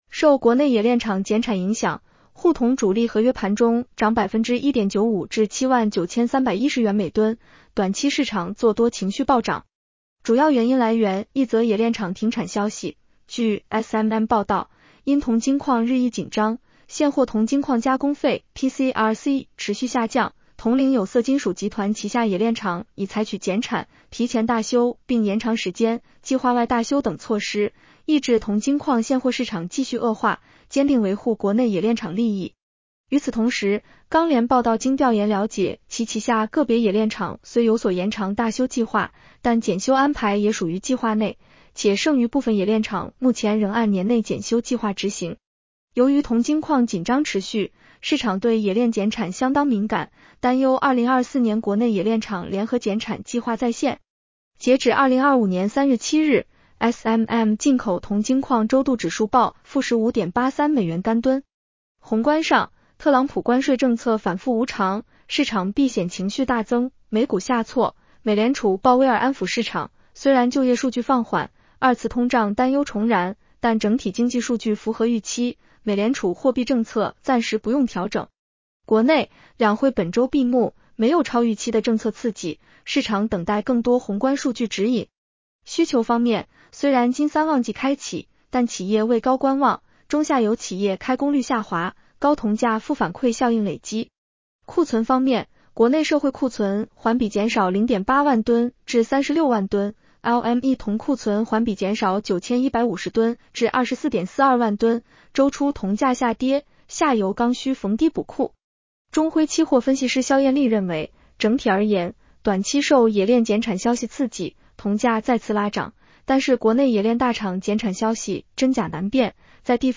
女声普通话版 下载mp3